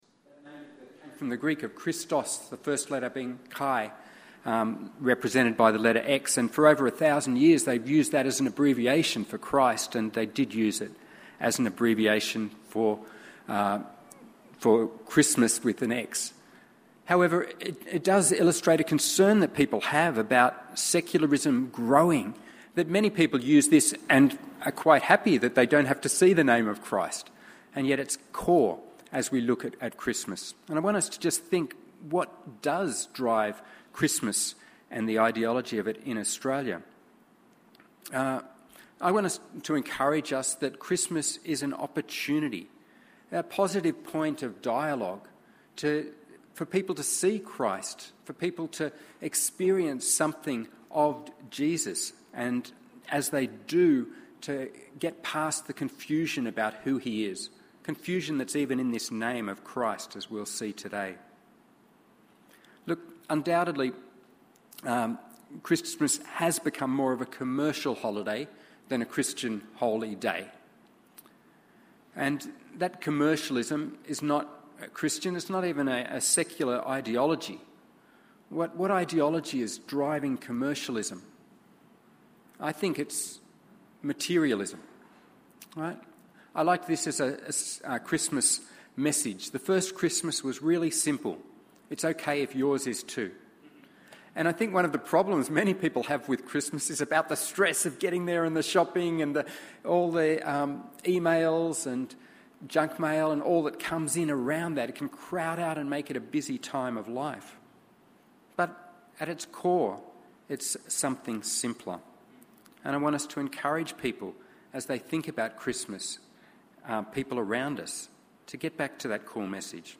by admin | Nov 29, 2020 | Christmas, Give Him the Name, Sermons 2020